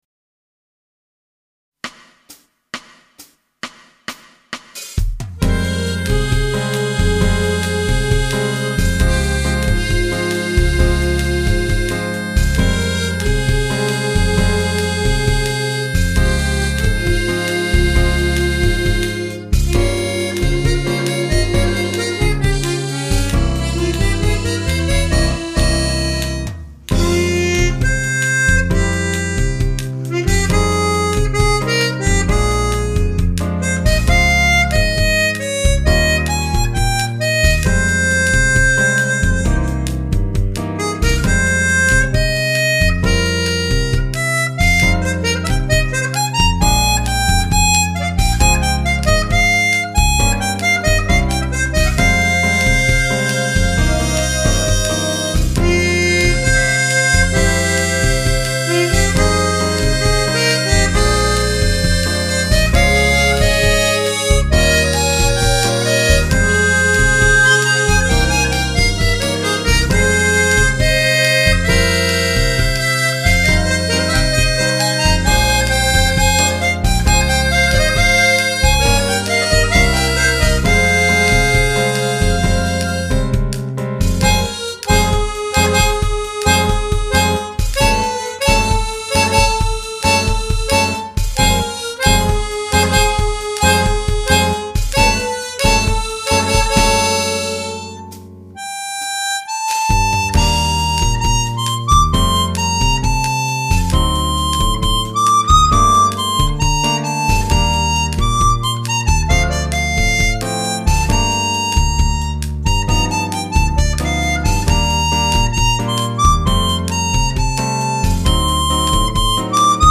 ここで公開しているmp3はビットレートを128kbpsに下げてあります。